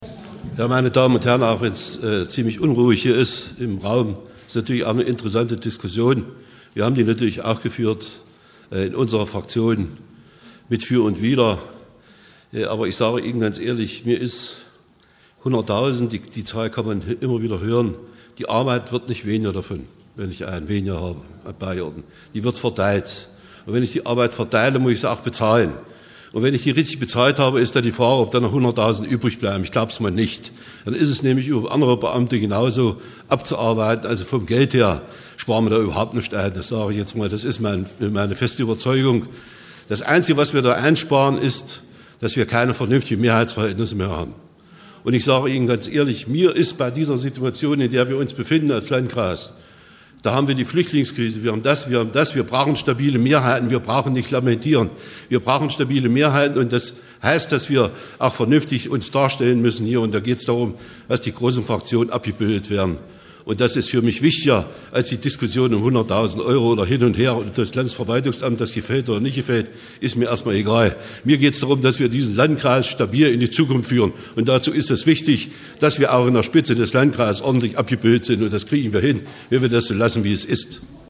Wir haben hier die Wortmeldungen als Audiobeiträge für Sie zusammengestellt, die uns freundlicherweise das Bürgerradio ENNO zur Verfügung gestellt hat.